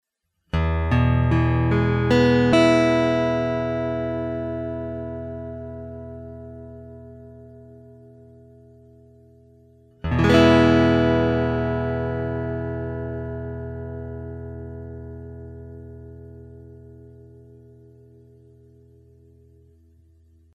Это прямо из гитары в мой 4-х дорожечный рекордер Fostex CompactFlash.
Для сравнения, вот мой GS (клен / englemann):